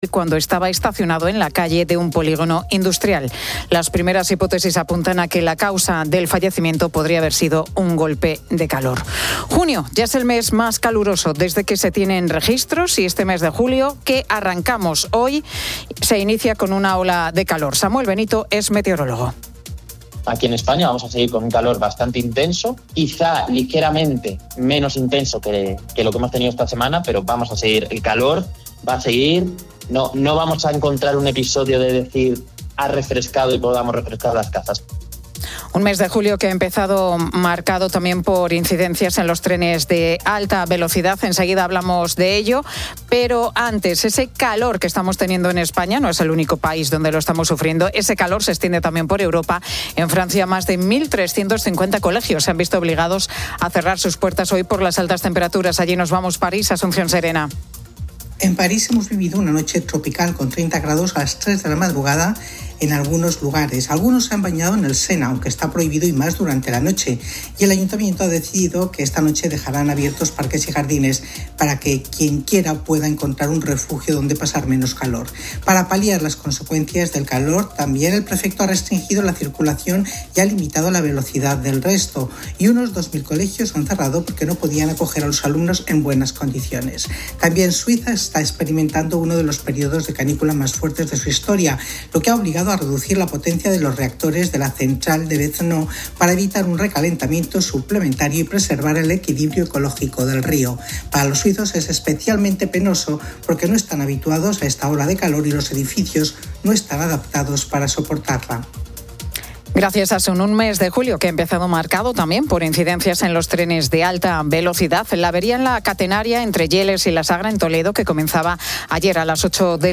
La Tarde 17:00H | 01 JUL 2025 | La Tarde Pilar García Muñiz ha entrevistado en directo a la cantante y actriz, Ana Belén, que acaba de sacar su último disco, "Vengo con ojos nuevos". Una entrevista íntima y nostálgica sobre la carrera personal y profesional de la artista.